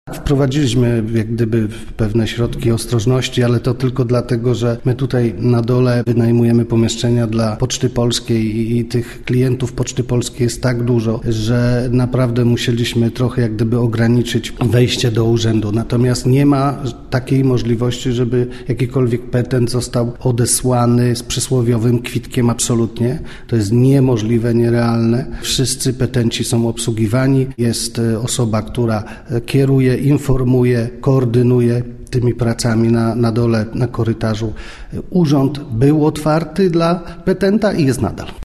– mówił wójt gminy Czastary, Dariusz Rejman.